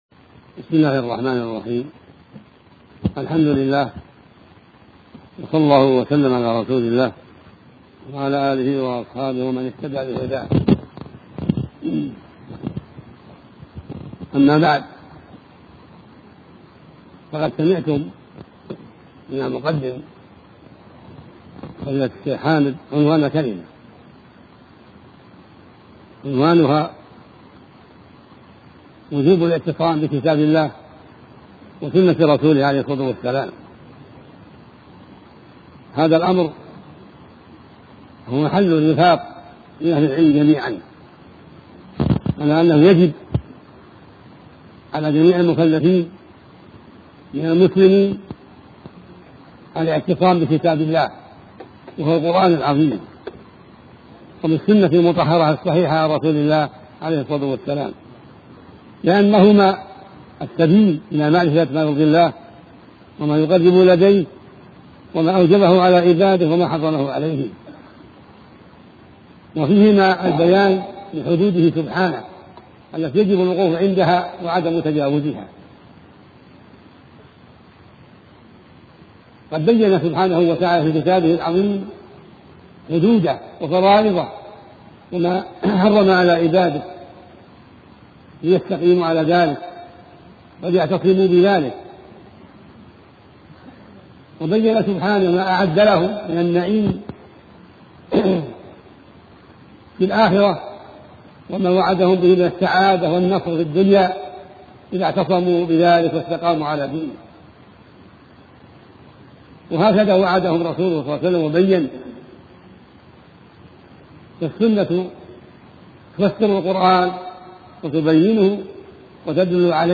شبكة المعرفة الإسلامية | الدروس | وجوب الاعتصام بالكتاب والسنة ولزوم منهج سلف الأمة |عبدالعزيز بن عبداللة بن باز